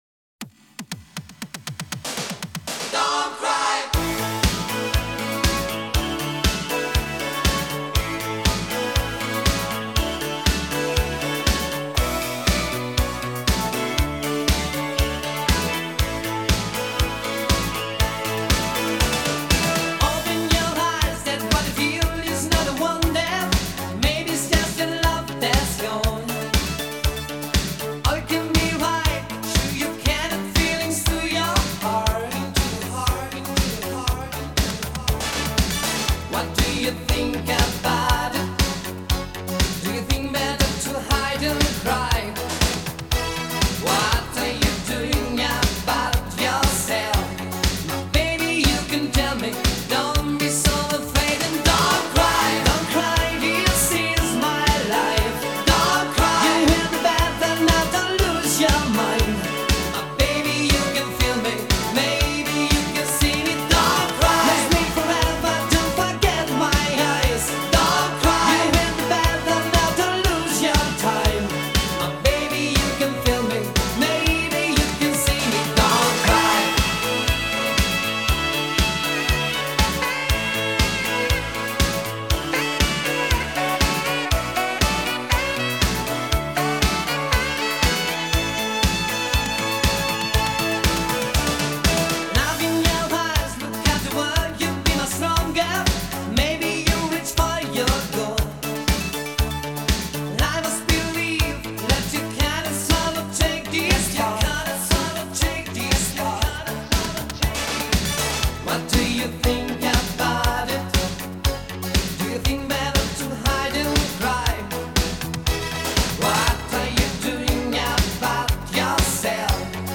- это уже классический евробит